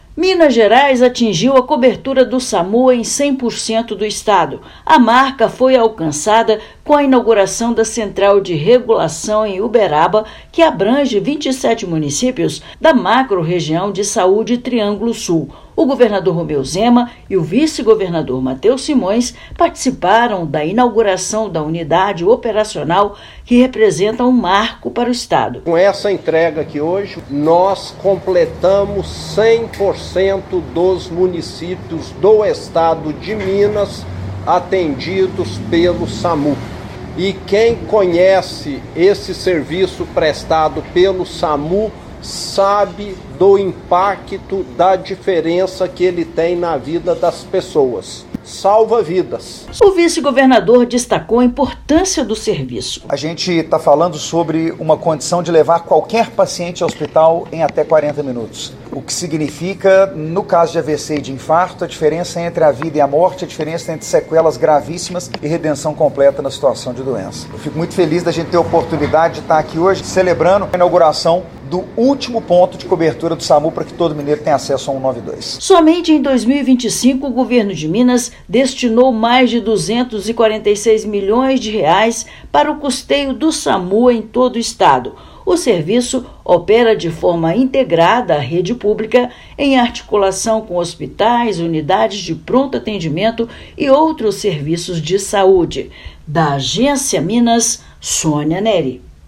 Com inauguração da Central de Regulação em Uberaba, serviço de urgência e emergência agora chega a todos os 853 municípios mineiros. Ouça matéria de rádio.